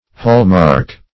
Hall-mark \Hall"-mark`\ (h[add]l"m[aum]rk`), n.